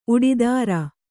♪ uḍidāra